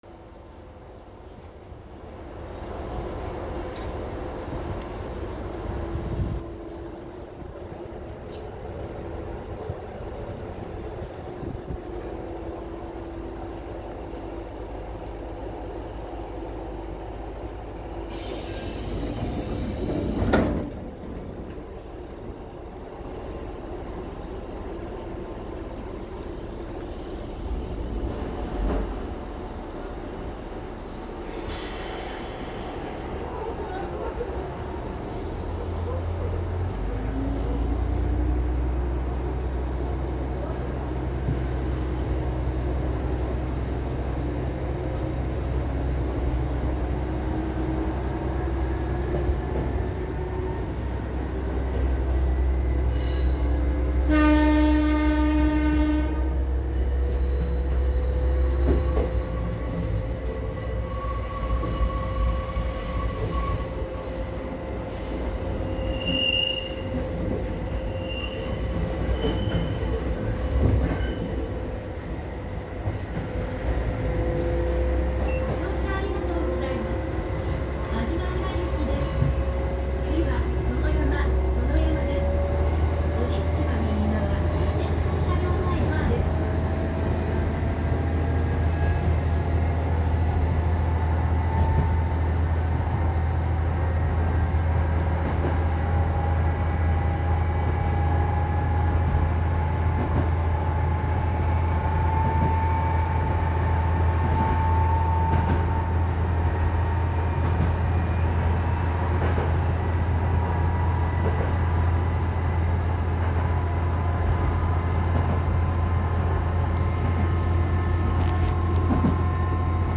・キハ11走行音